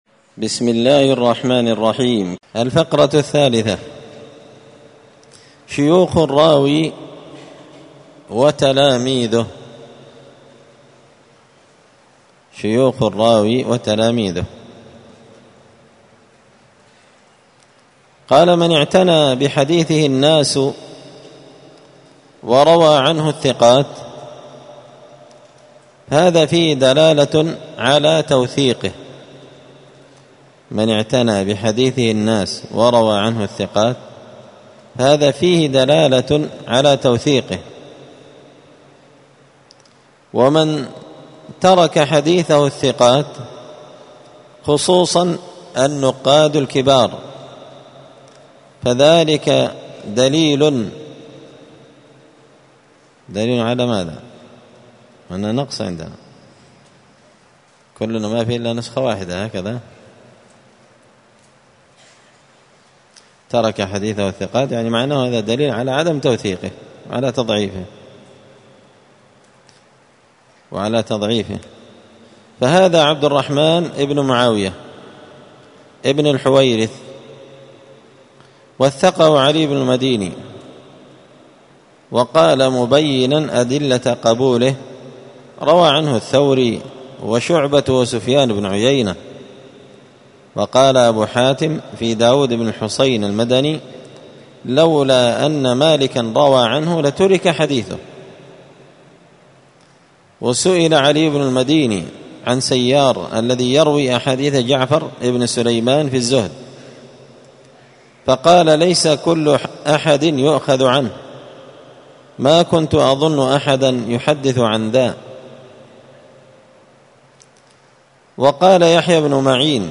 *الدرس الخامس (5) شيوخ الراوي وتلاميذه*